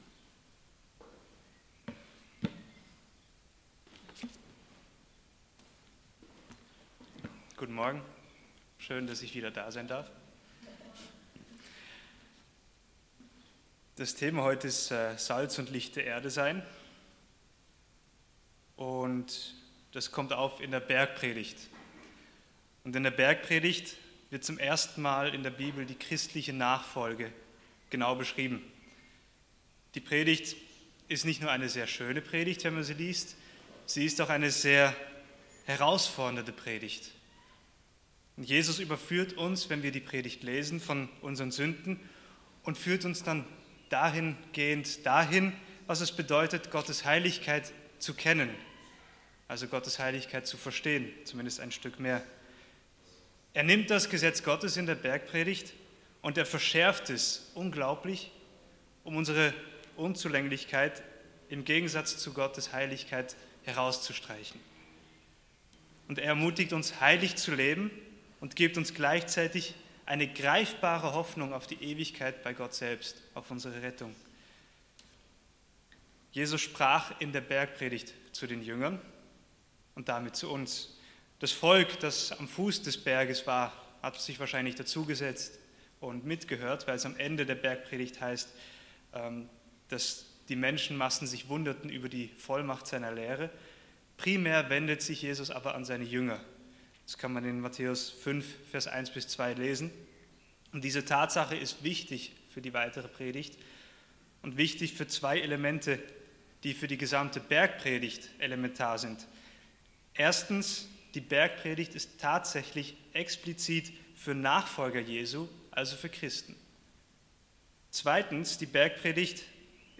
Predigt vom 05.06.2016